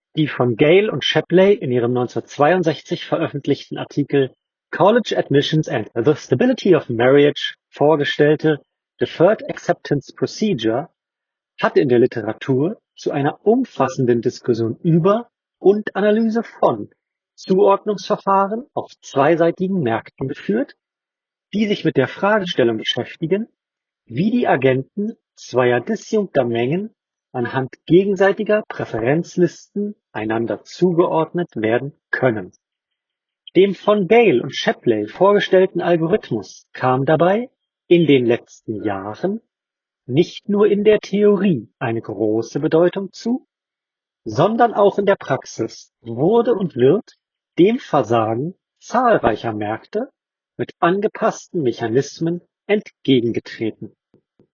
Sowohl über die Ohrhörer als auch das Super Mic ist die Geräuschunterdrückung der Umgebung hoch und von Straßenlärm und Vogelgezwitscher ist so gut wie nichts mehr zu hören.
Nothing Ear (3) Ohrhörer – Mikrofonqualität